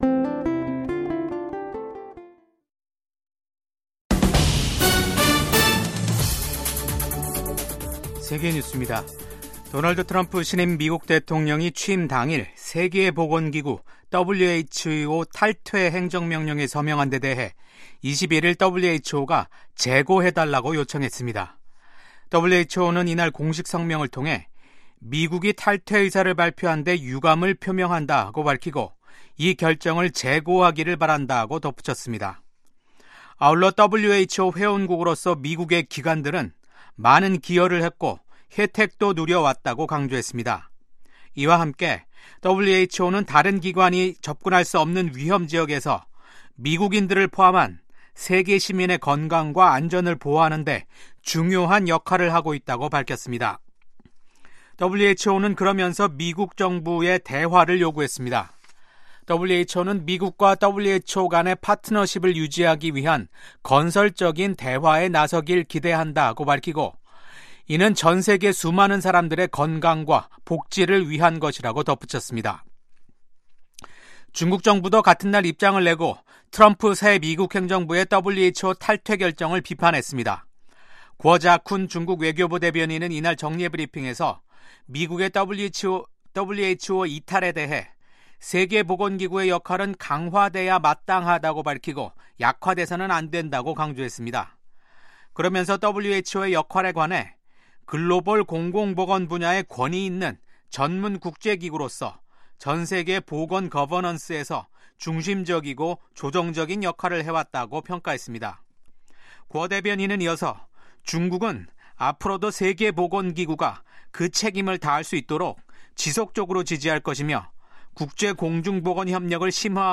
VOA 한국어 아침 뉴스 프로그램 '워싱턴 뉴스 광장'입니다. 도널드 트럼프 미국 대통령이 4년 만에 백악관에 복귀했습니다. 취임 첫날 트럼프 대통령은 북한을 ‘핵보유국’이라고 지칭해 눈길을 끌었습니다.